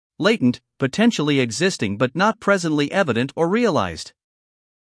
英音/ ˈleɪt(ə)nt / 美音/ ˈleɪt(ə)nt /